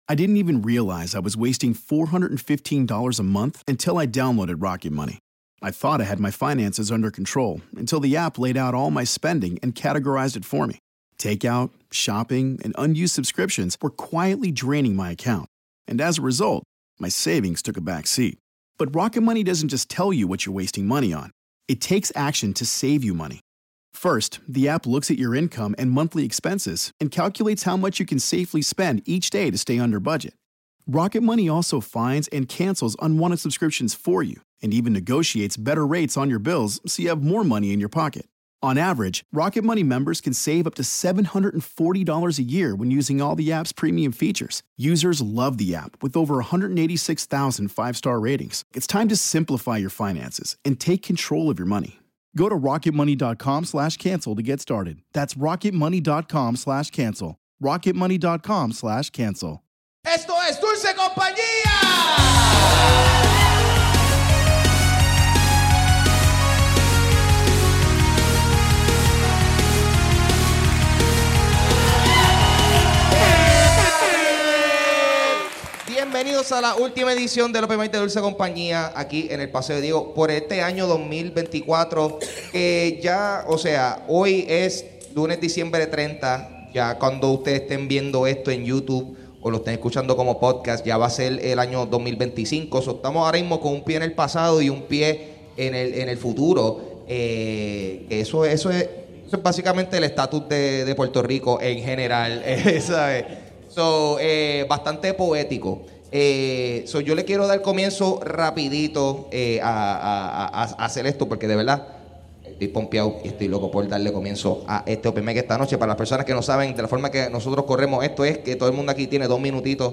¡Oficialmente cerramos el 2024 con el último open mic del año!